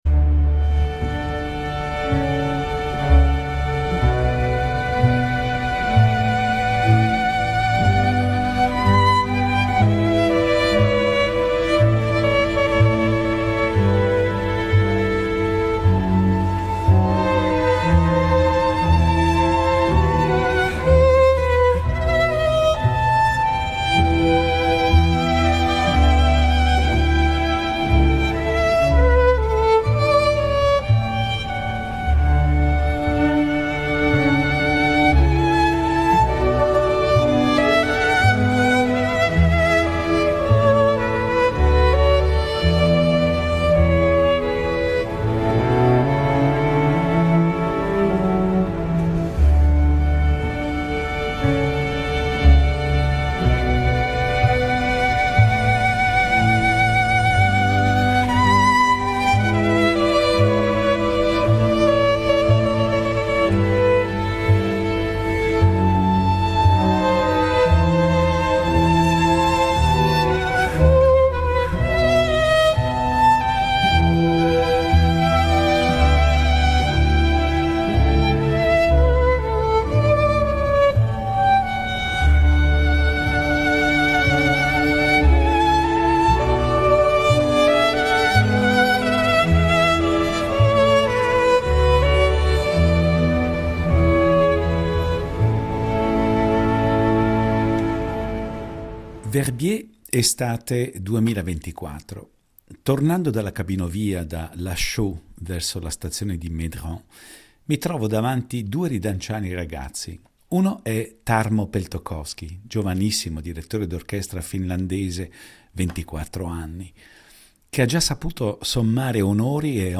Incontro con il violinista svedese